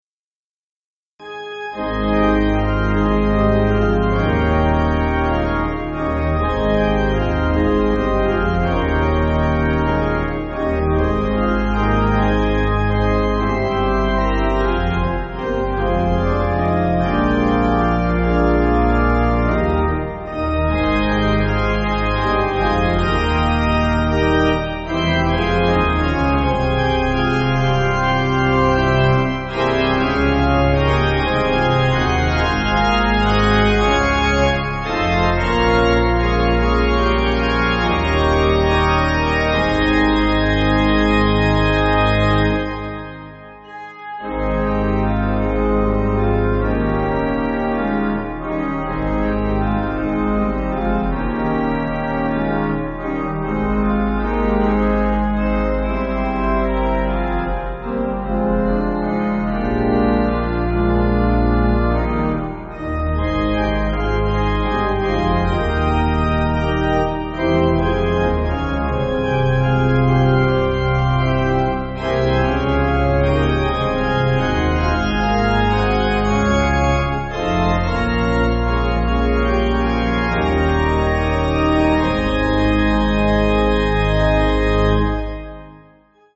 Organ
(CM)   4/Ab